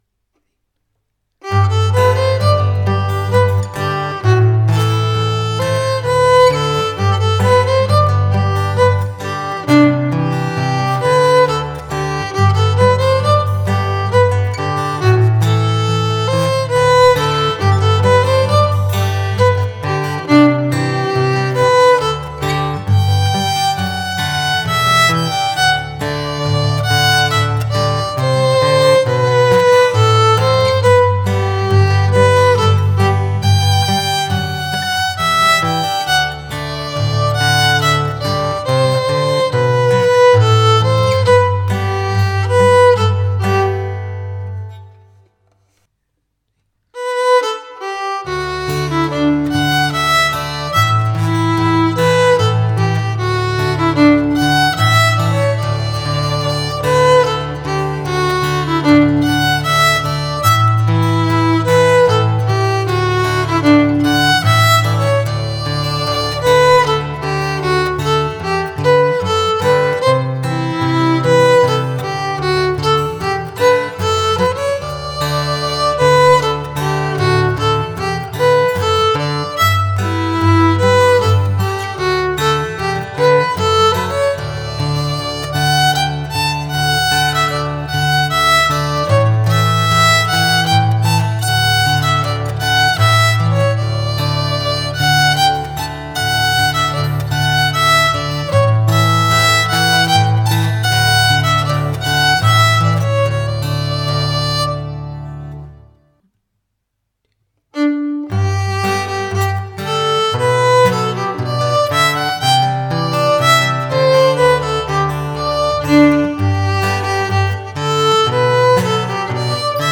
Tune and guitar (Rusty Gulley, Reedhouse Rant, The Trincentannial)
Morpeth-26-tune-and-guitar.mp3